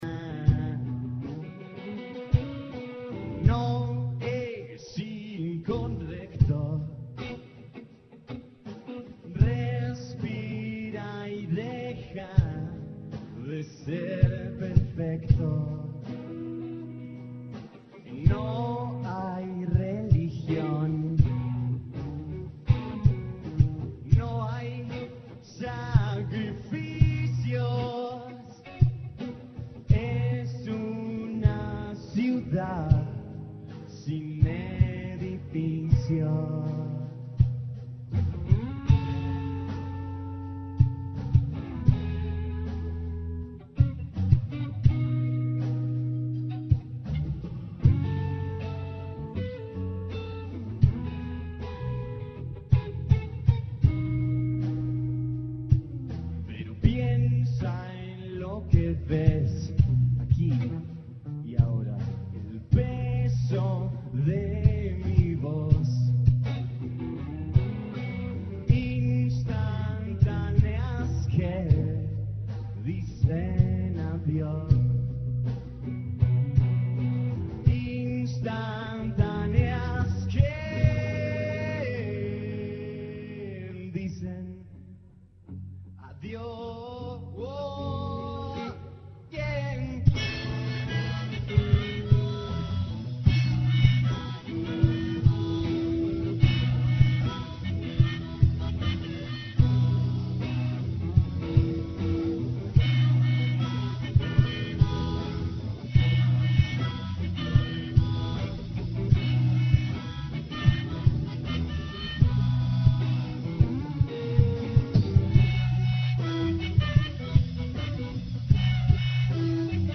The report also includes presenters’ remarks on the environment, security, the diversity of genres, and the cultural significance of the festival.